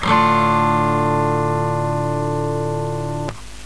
virtual guitar
Dm